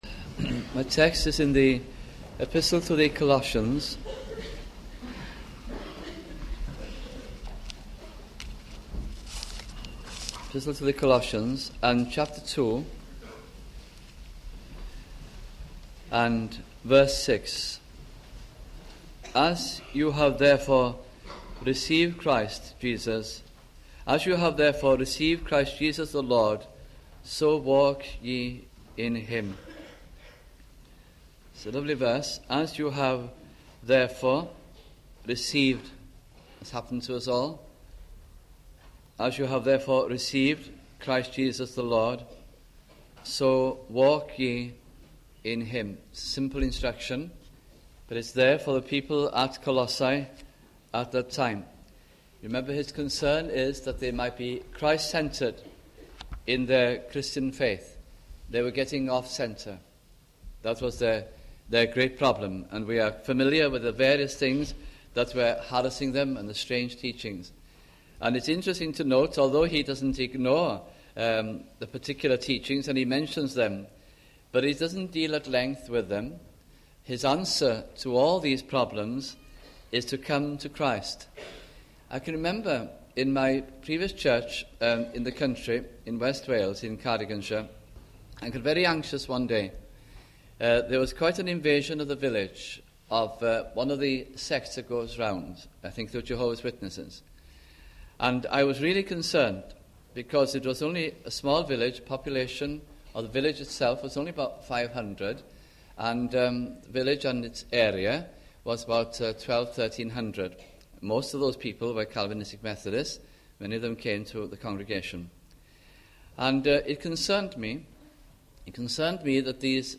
» Colossians Series 1988 - 1989 » sunday morning messages